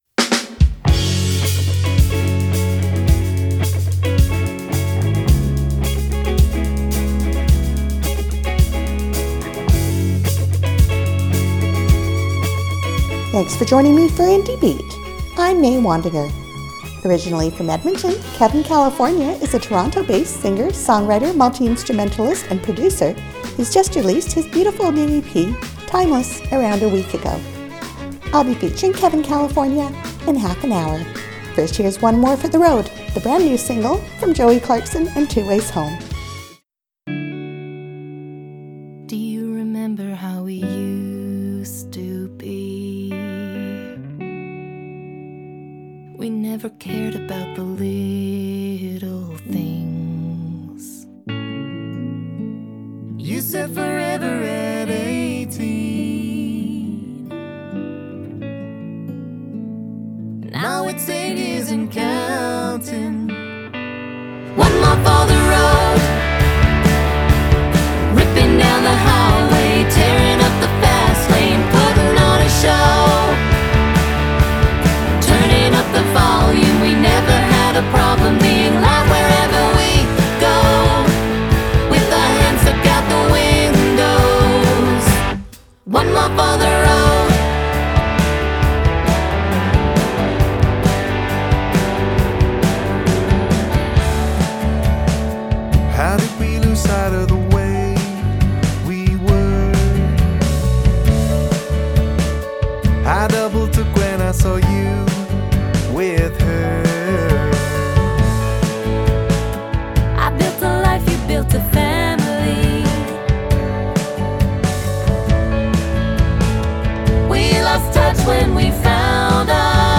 28 min of Western Canadian indie music mix